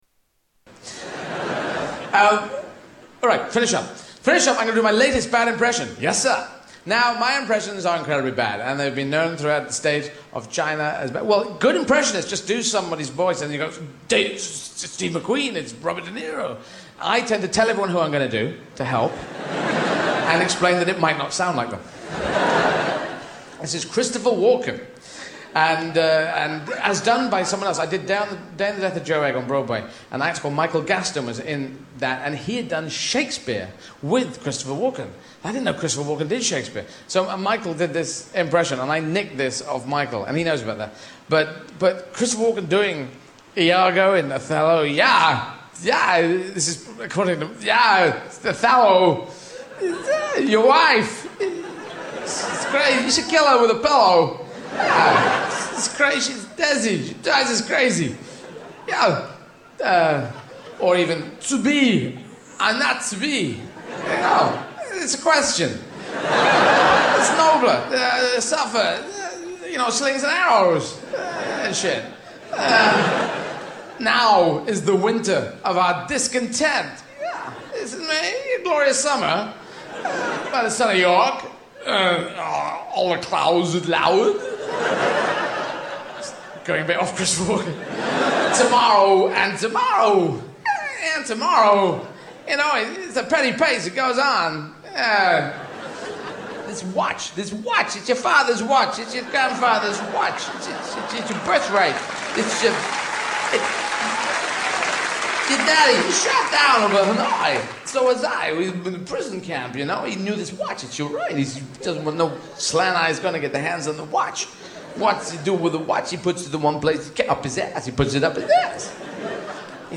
Tags: Comedians Eddie Izzard Eddie Izzard Soundboard Eddie Izzard Clips Stand-up Comedian